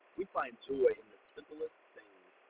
描述：重建信号10dB
Tag: SNR 10分贝 重构